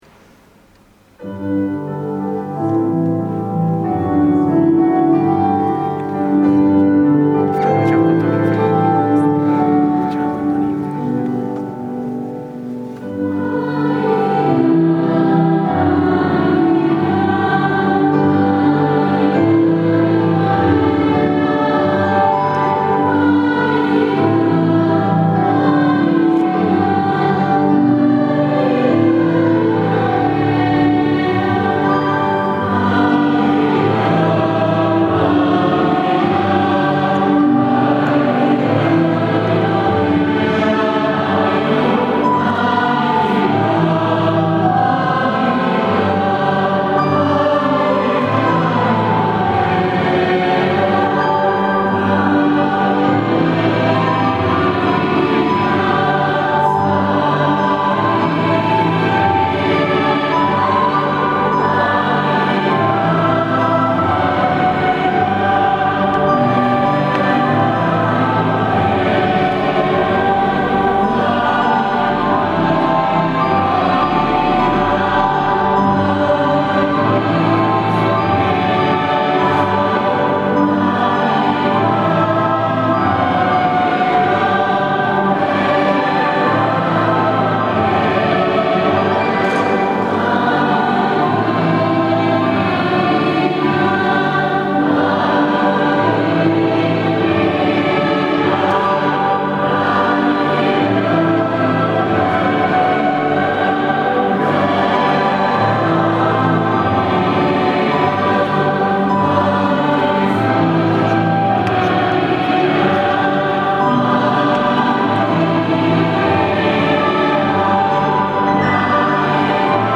Sabato 07 ottobre 2017 la corale ha animato la S. Messa in occasione della festività della Madonna del Rosario.